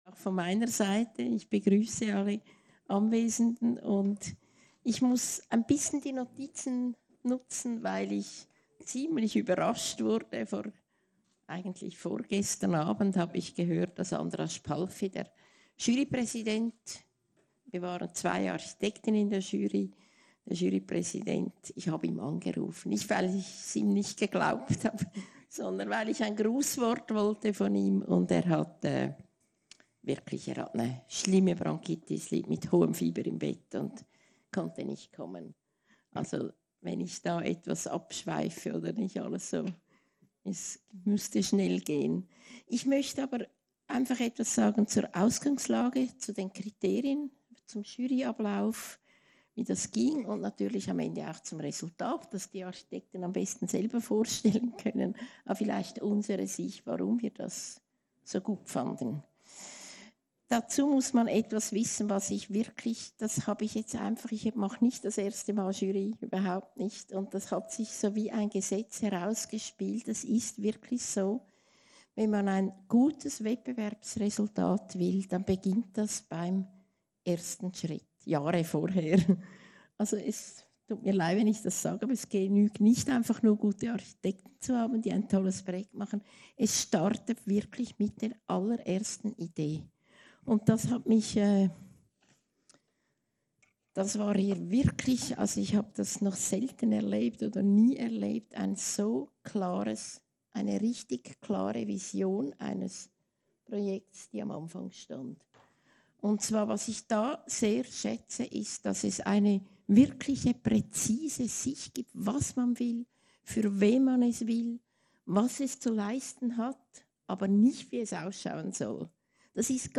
Pressekonferenz zum Ergebnis des Interdisziplinären Planungswettbewerbs TINNE junges museum klausen, 24.02.2023, 17 Uhr